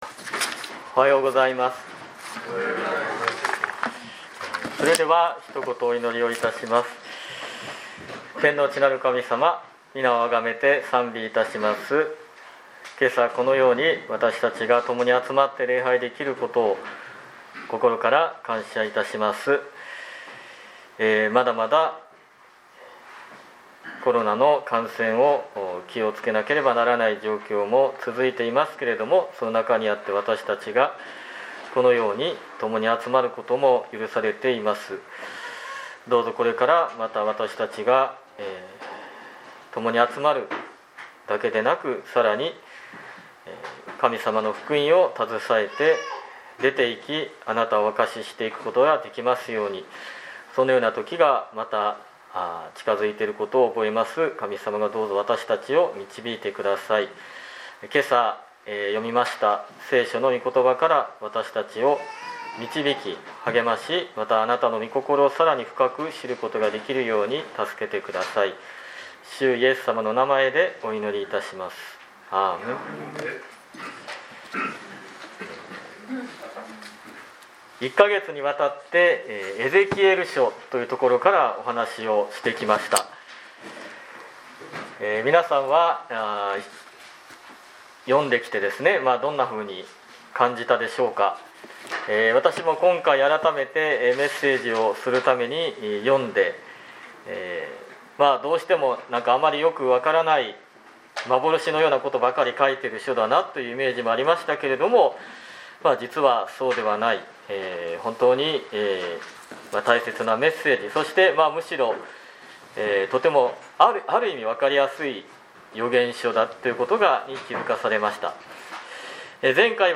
2022年10月30日礼拝メッセージ